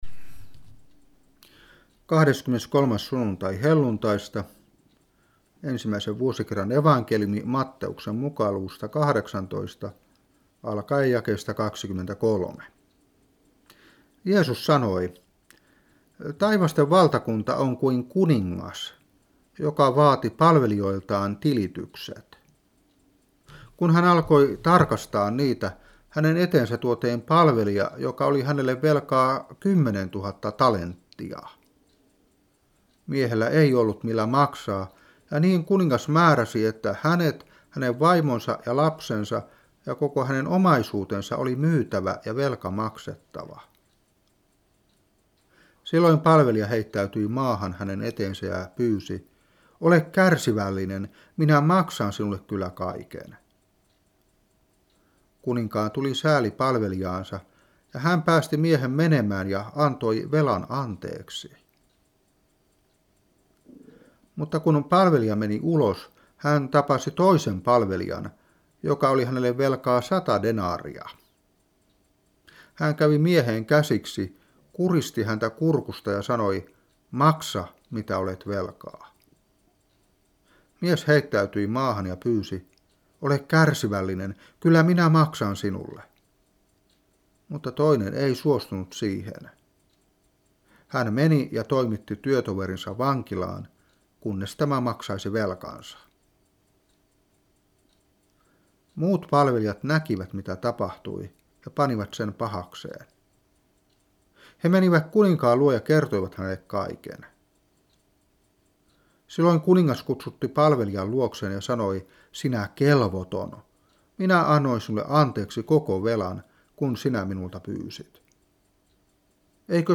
Saarna 2001-11.